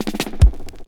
22DR.BREAK.wav